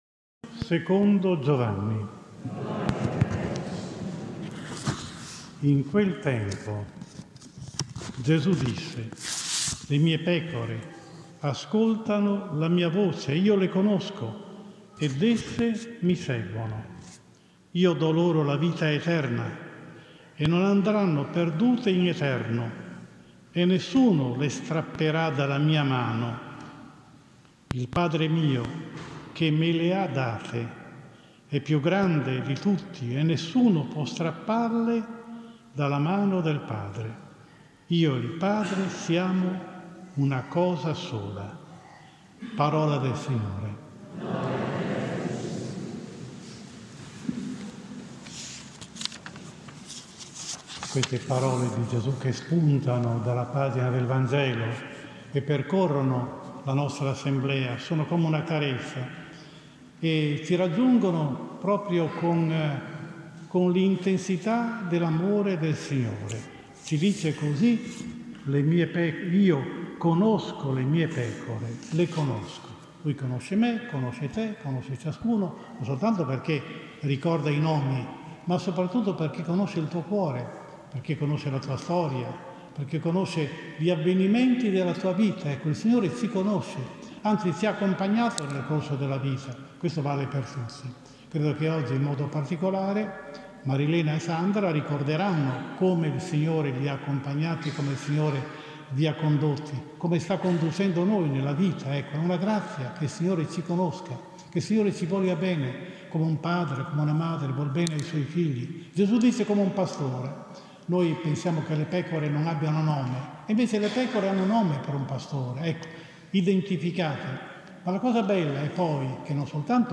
8 maggio 2022 – Domenica Quarta di Pasqua: omelia